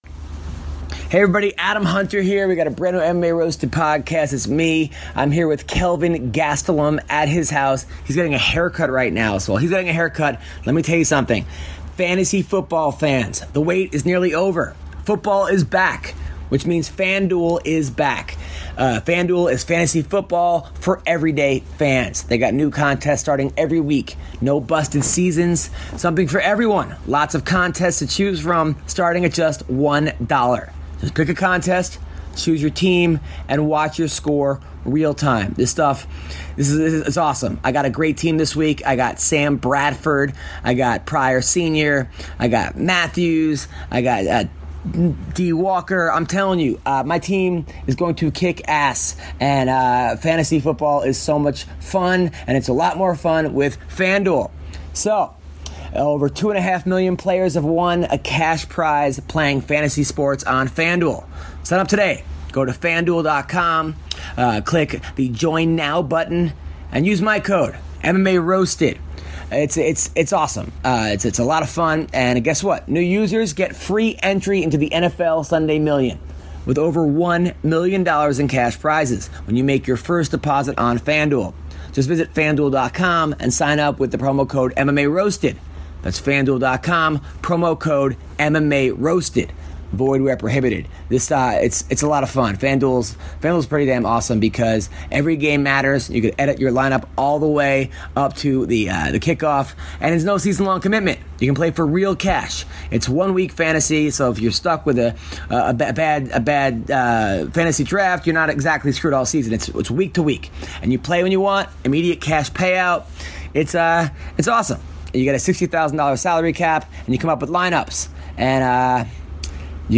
travels to the home of UFC fighter Kelvin Gastelum for a special episode of the MMA Roasted podcast.